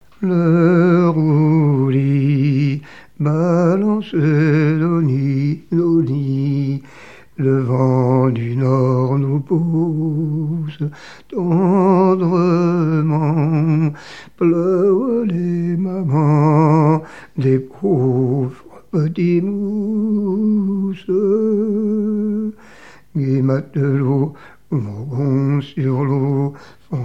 témoignages et chansons maritimes
Pièce musicale inédite